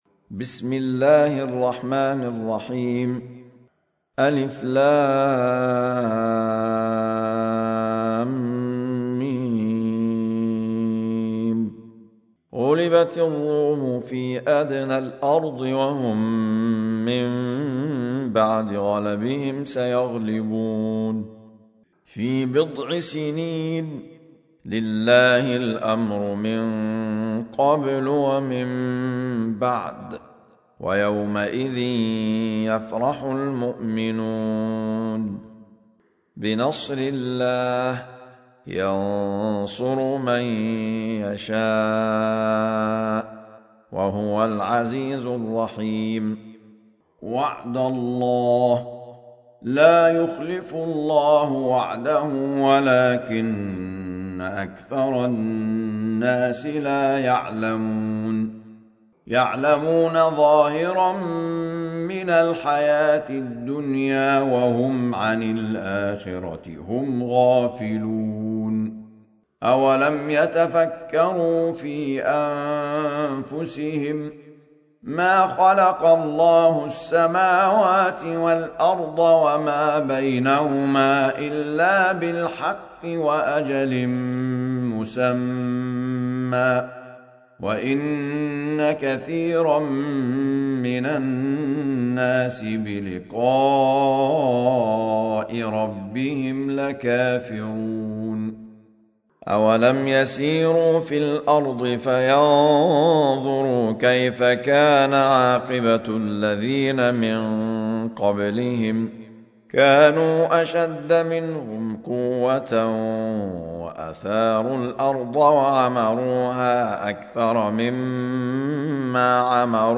سورة الروم | القارئ أحمد عيسي المعصراوي